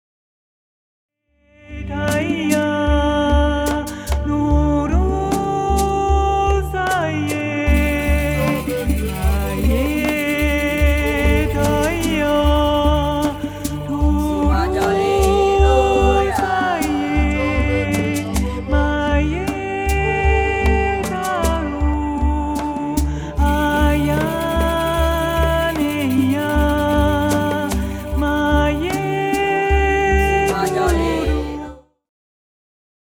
Meditative / Poesie
Momentum-Aufnahmen